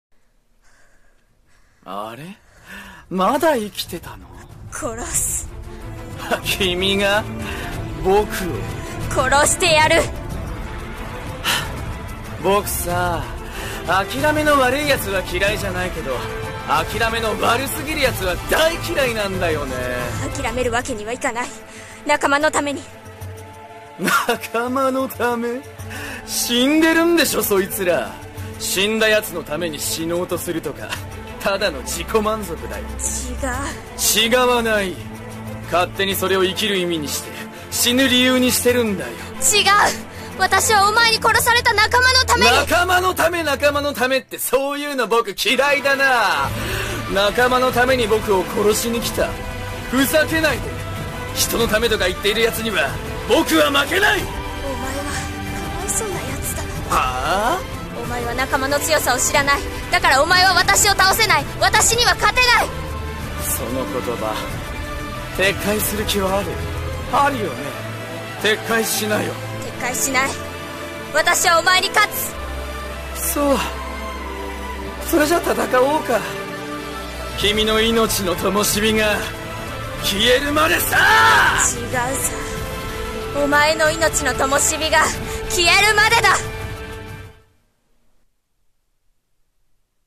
【声劇 台本】最終決戦【掛け合い】(コラボ用)